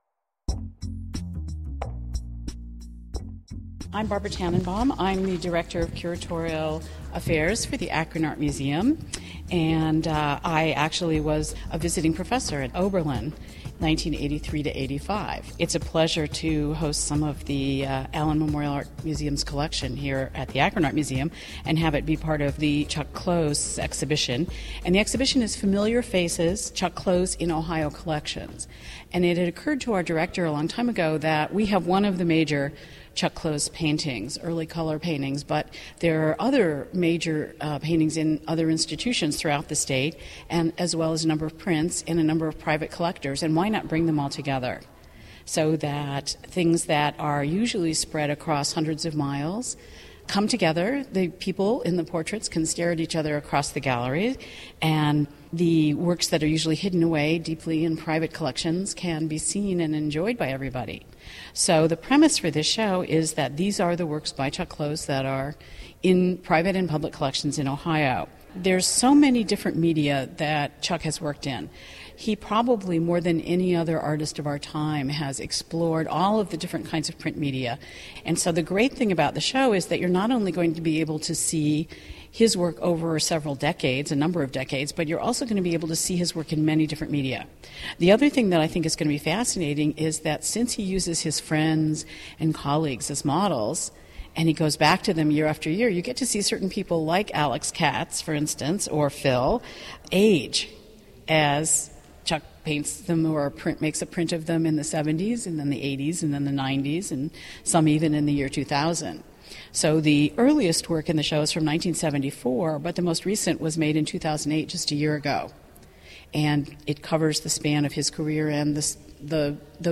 These podcasts are taken from conversations in the Akron galleries